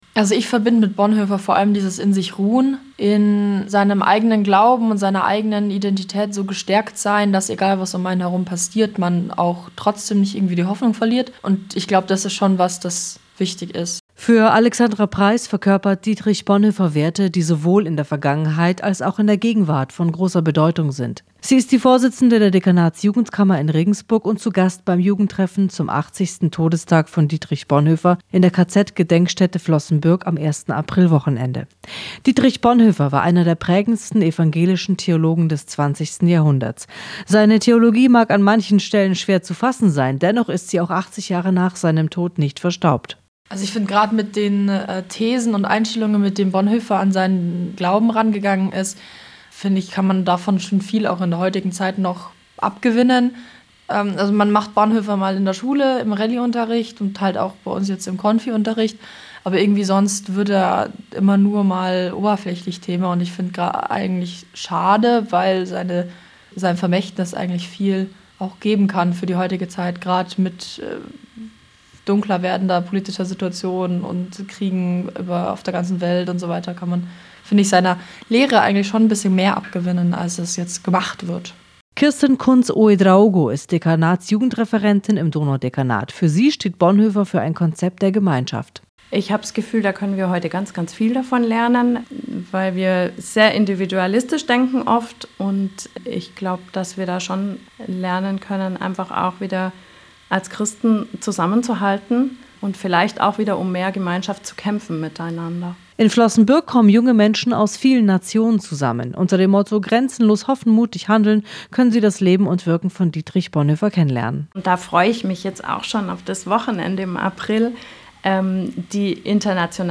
Radiobeitrag